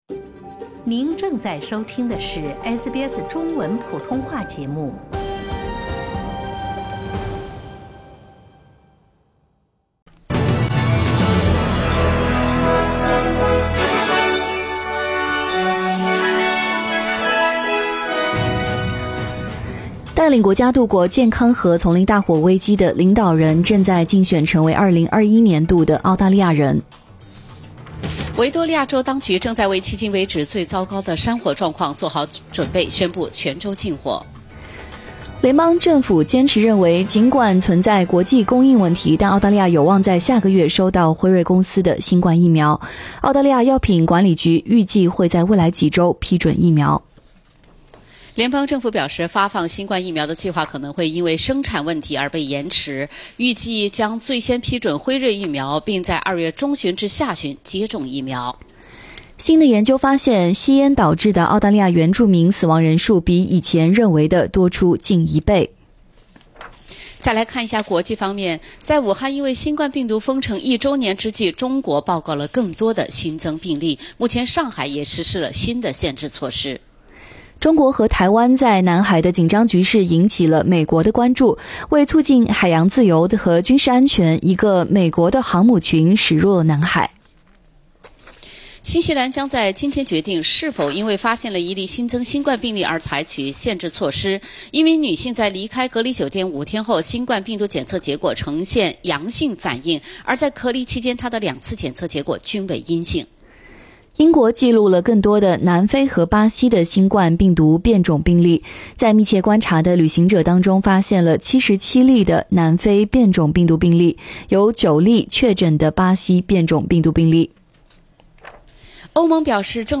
SBS早新闻（1月25日）